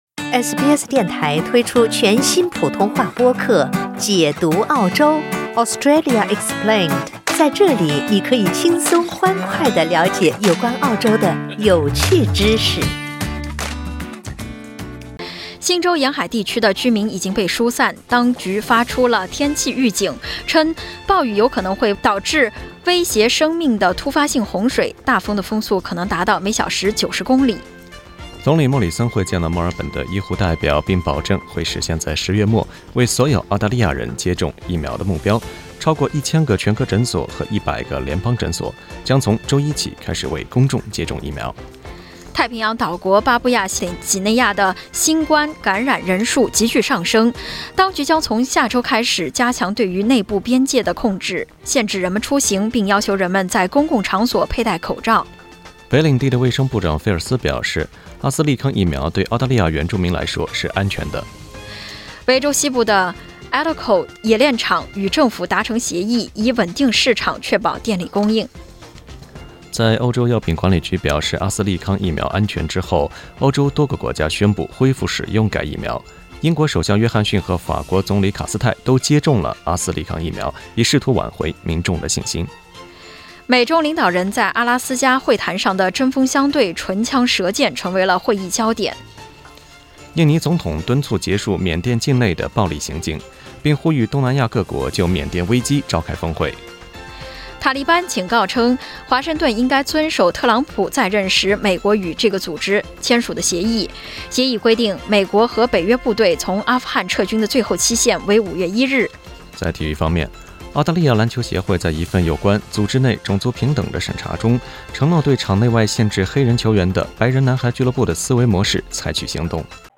SBS早新聞（3月20日）